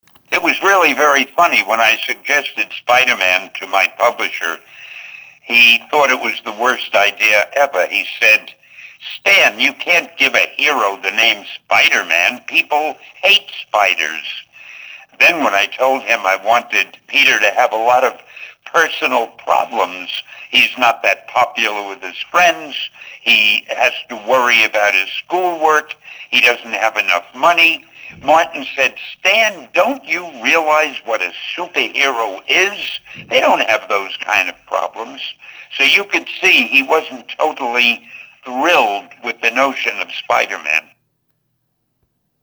Unsterbliche Worte von Stan Lee (mp3):